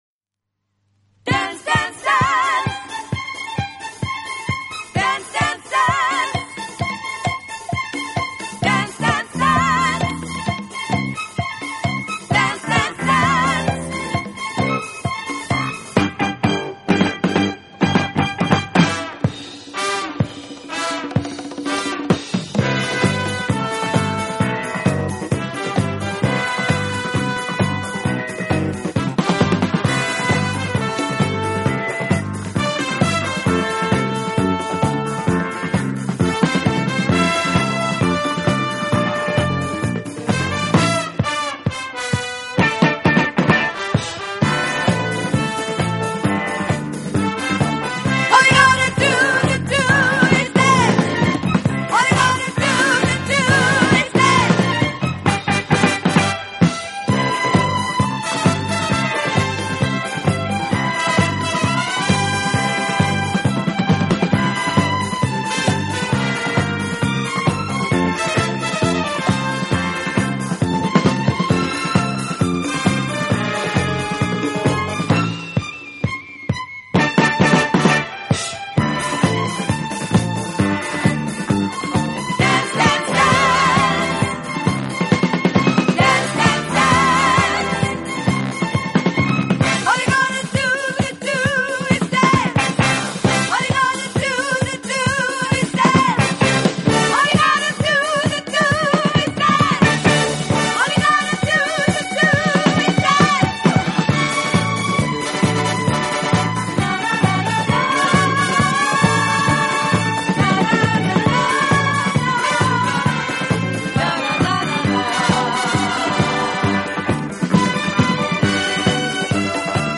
【轻音乐】